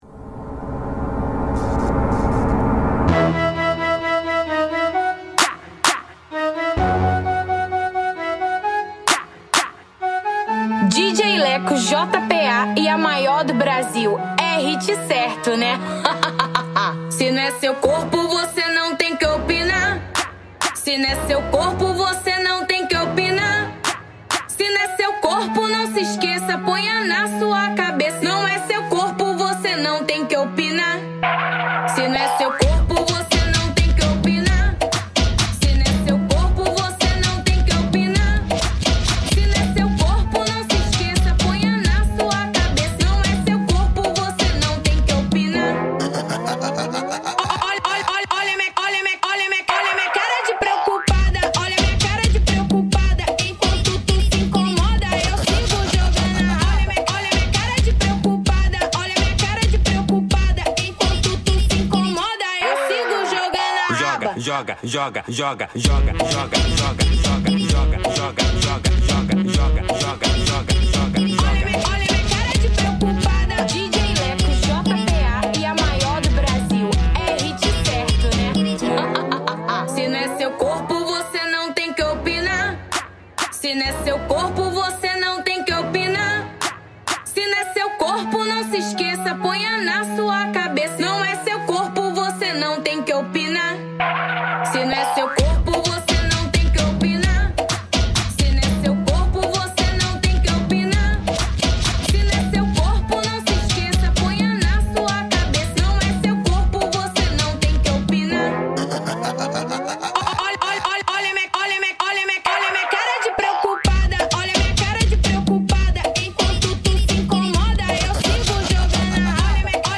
2024-02-27 13:33:07 Gênero: Funk Views